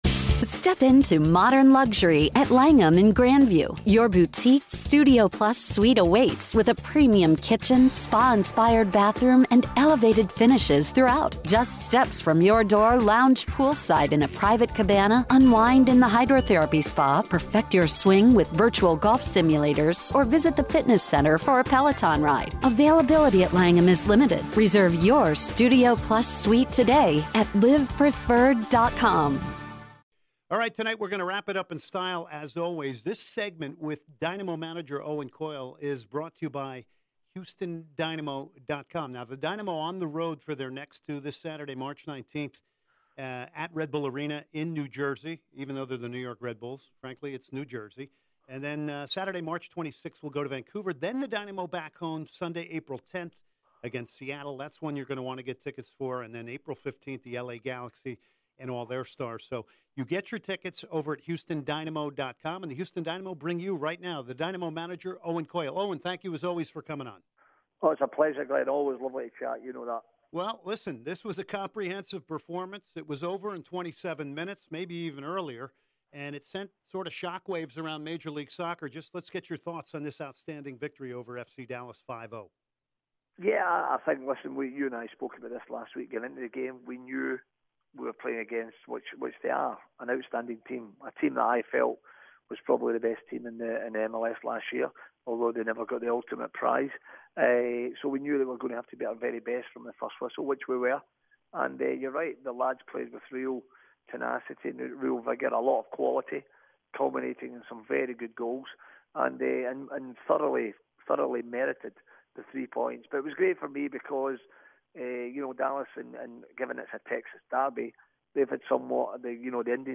03/15/16 Owen Coyle interview